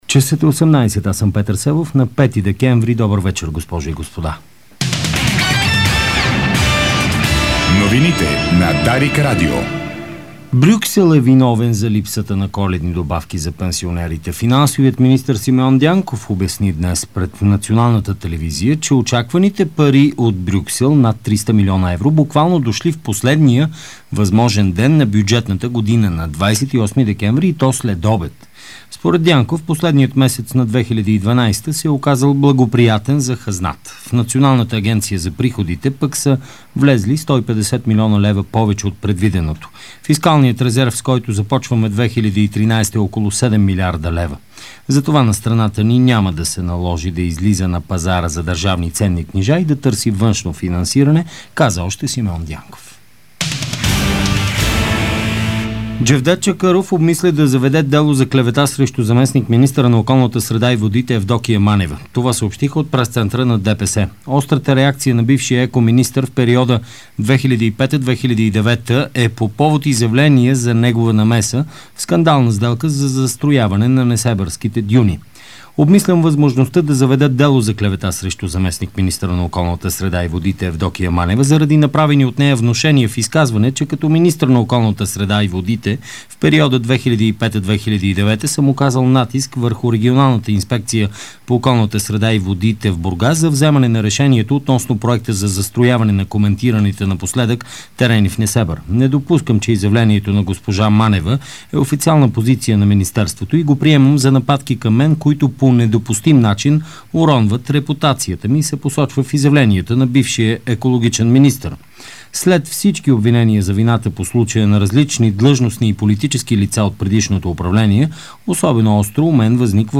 Обзорна информационна емисия